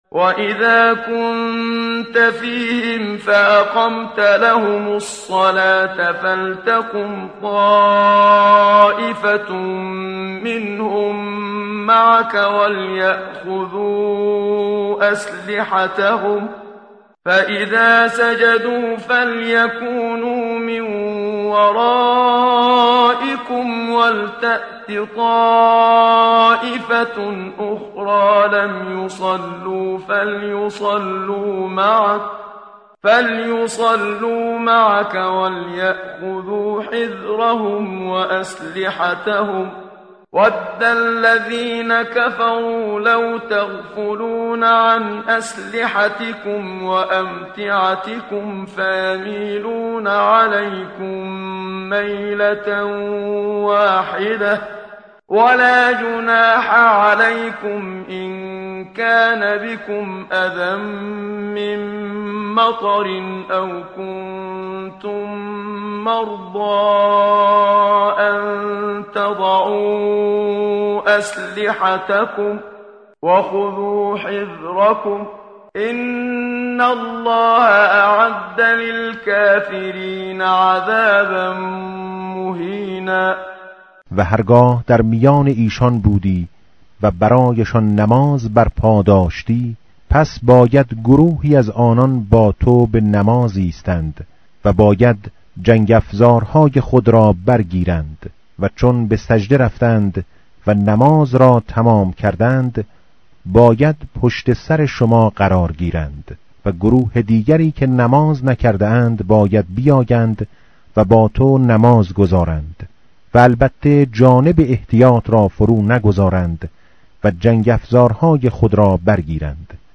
متن قرآن همراه باتلاوت قرآن و ترجمه
tartil_menshavi va tarjome_Page_095.mp3